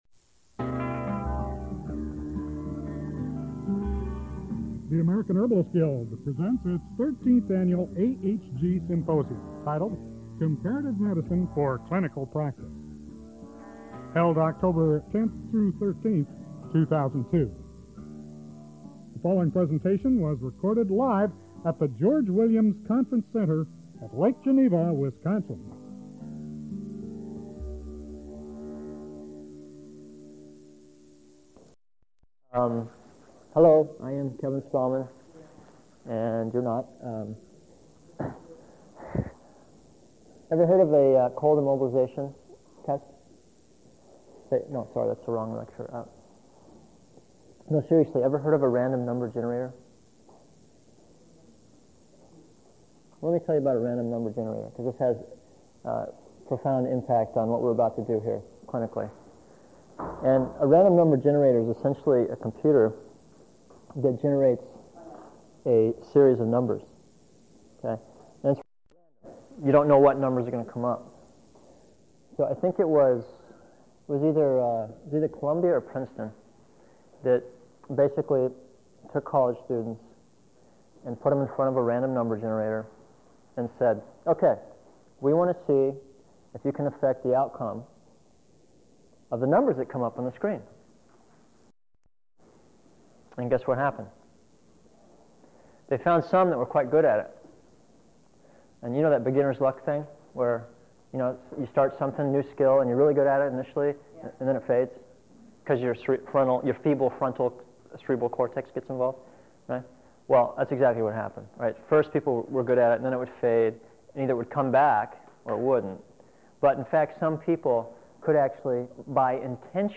Webinar recording